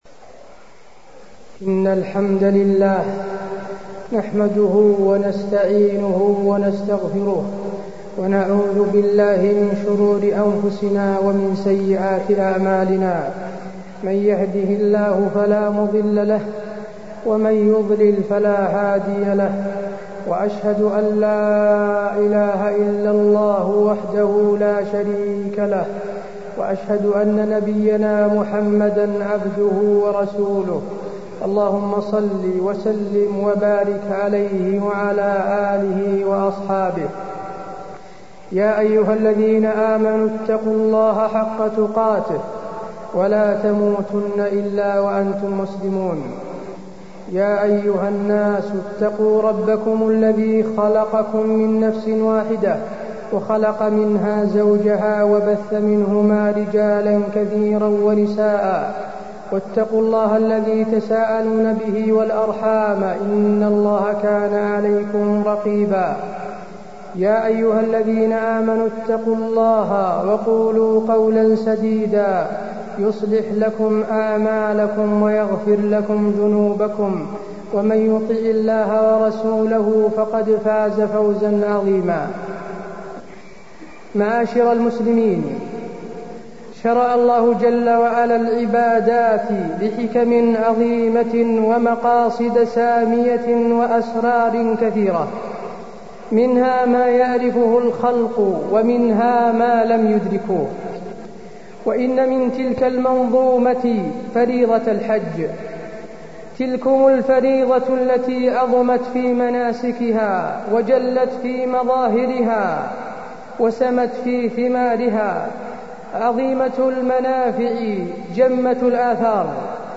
تاريخ النشر ٦ ذو الحجة ١٤٢٣ هـ المكان: المسجد النبوي الشيخ: فضيلة الشيخ د. حسين بن عبدالعزيز آل الشيخ فضيلة الشيخ د. حسين بن عبدالعزيز آل الشيخ الحج وفضل عشر من ذي الحجة